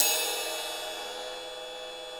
RIDE20.wav